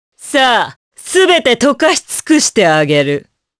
Morrah-vox-select_jp.wav